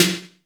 Index of /90_sSampleCDs/Masterbits - Soniq Elements/SPECL FX 9+8/WET SNARES
WET S808  -L 2.wav